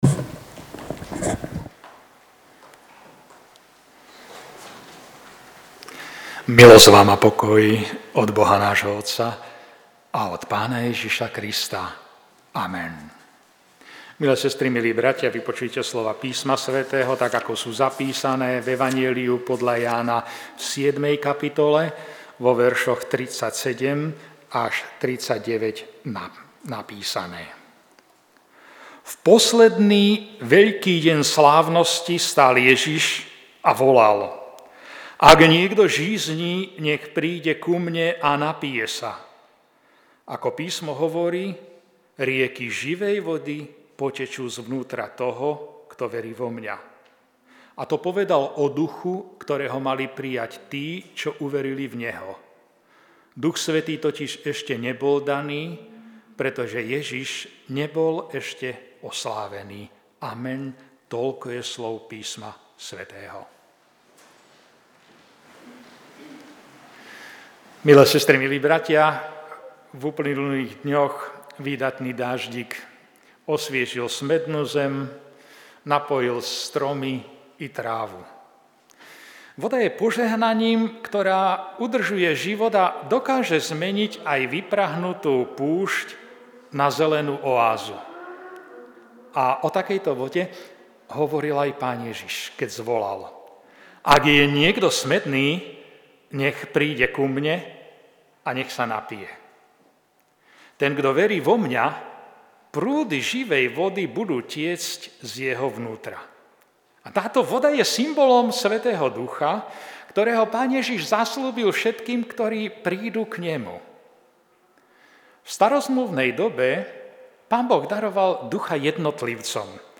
káže